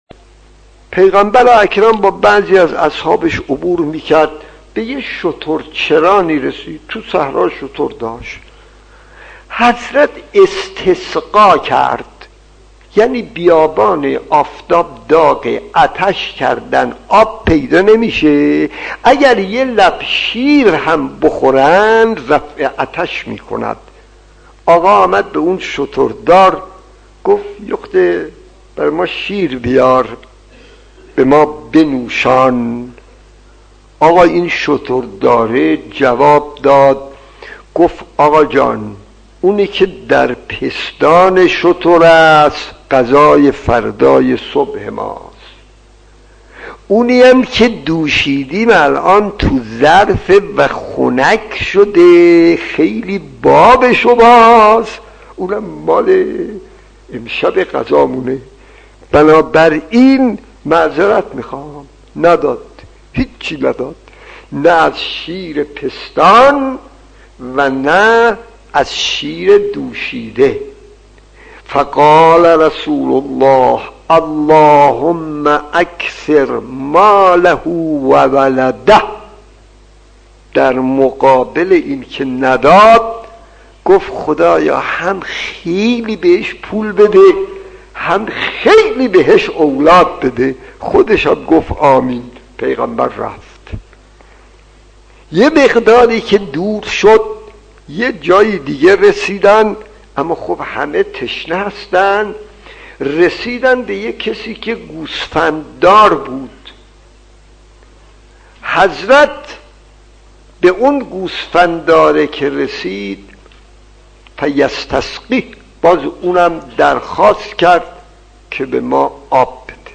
داستان 42 : پیامبر و شترچران خطیب: استاد فلسفی مدت زمان: 00:05:18